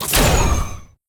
bullet_spider.wav